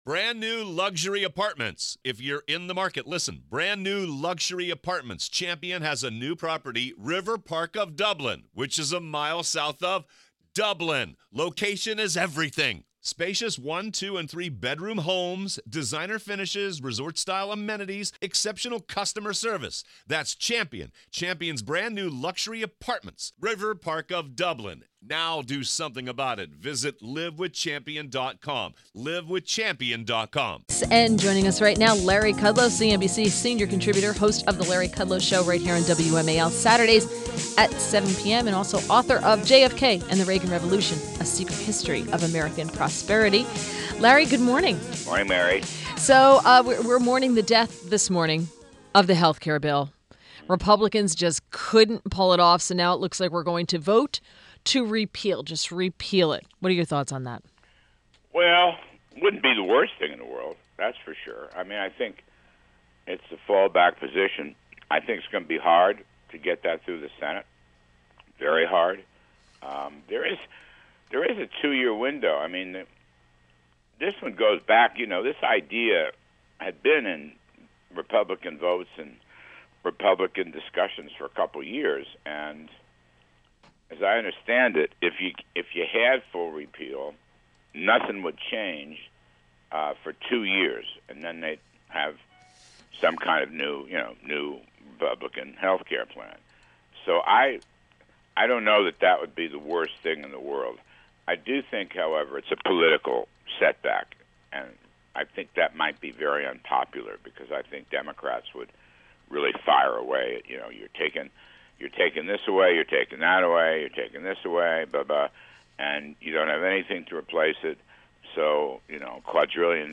WMAL Interview - LARRY KUDLOW 07.18.17